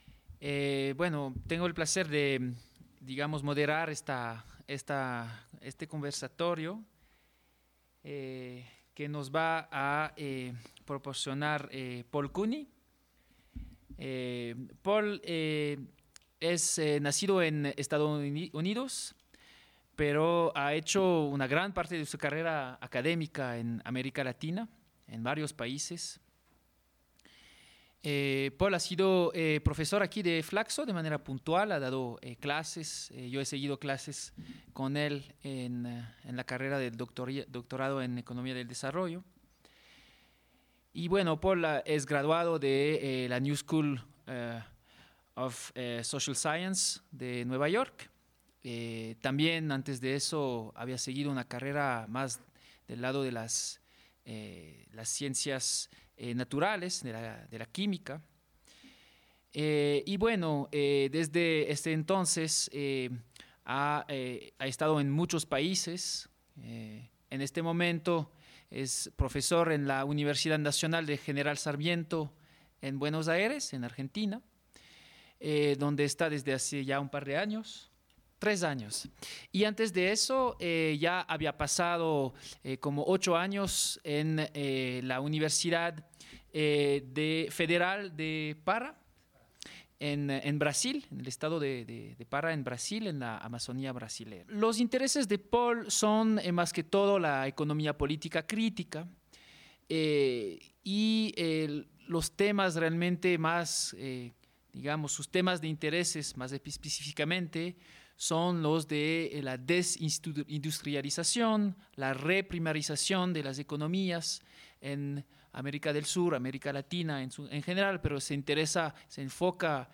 presentó la charla